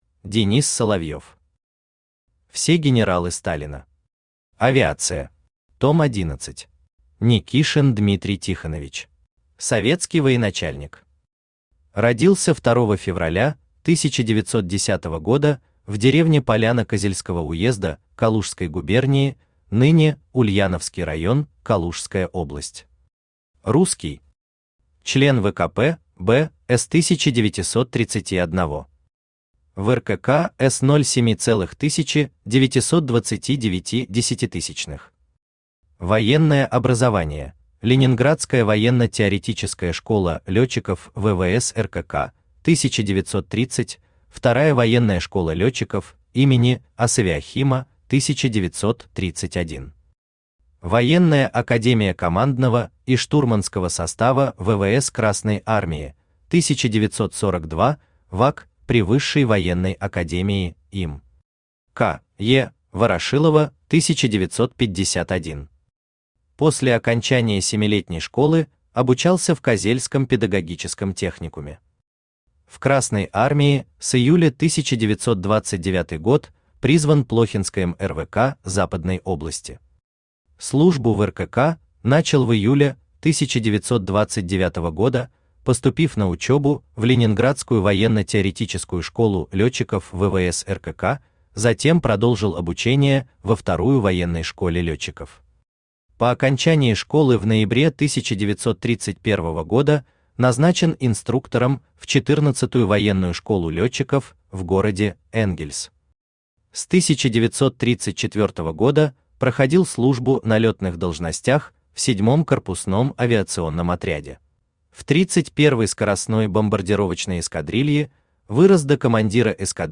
Аудиокнига Все генералы Сталина. Авиация. Том 11 | Библиотека аудиокниг
Том 11 Автор Денис Соловьев Читает аудиокнигу Авточтец ЛитРес.